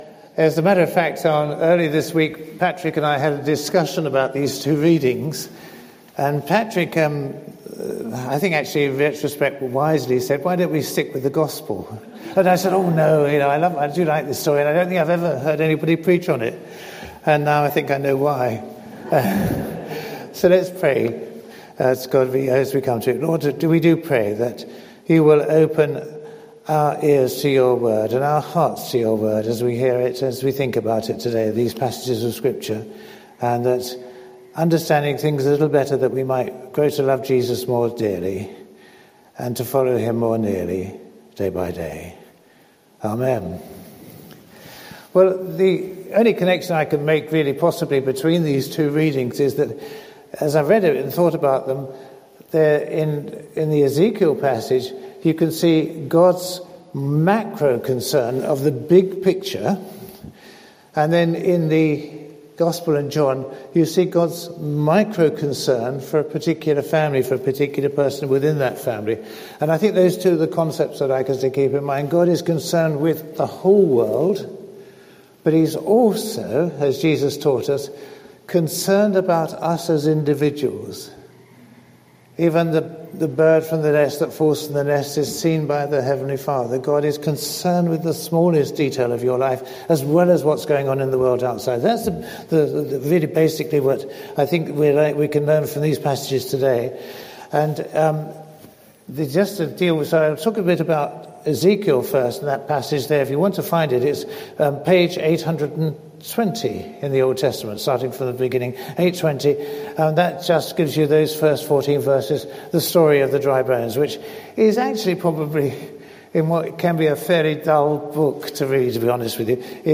Lent 2026 The raising of Lazarus 22 March, 2026 From the rasing of the dead bones in Ezekiel to Jesus raising Lazarus from death, God shows his power and compassion. Preacher